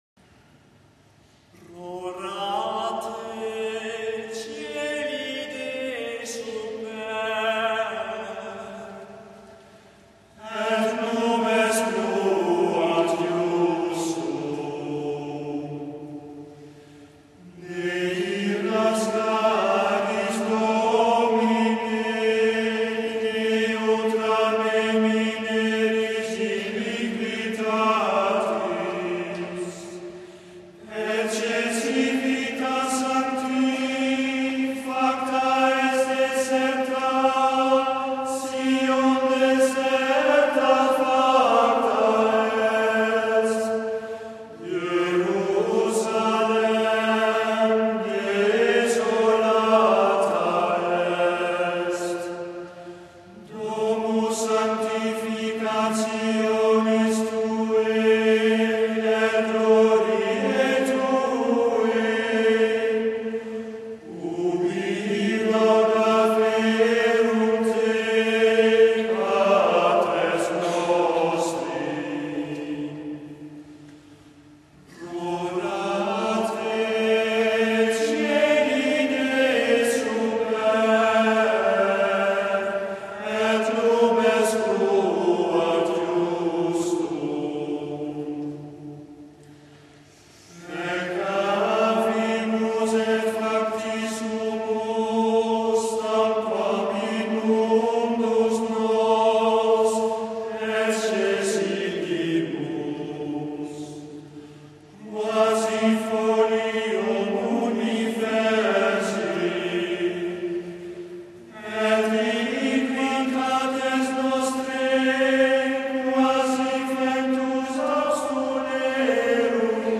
Eglise Saint-François-de-Paule Fréjus - Vigile de Noël
Chapelle Saint-François-de-Paule - Place Agricola - Fréjus